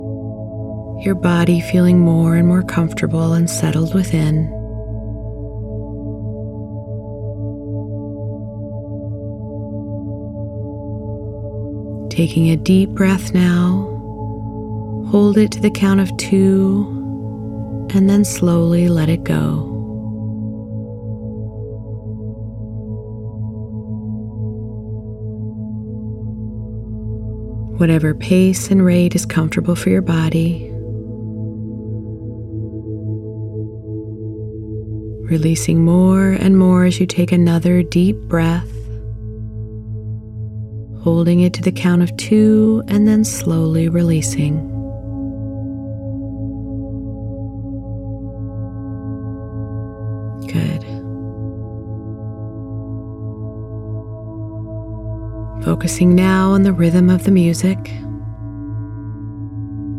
This meditation is a daily fear release, where you learn to “drain out” what is not wanted and replace it with what you want in your pregnancy or birth.
To be used during a nap or meditation, where you need to have a “count up” at the end so you feel fully alert to go about your day after listening(not to be listened to or used when operating any automobile or machinery).